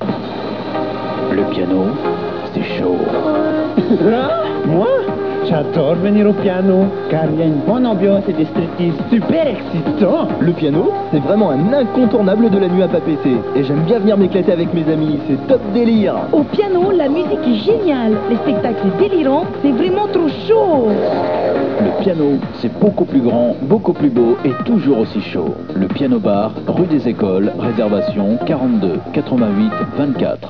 Publicités radio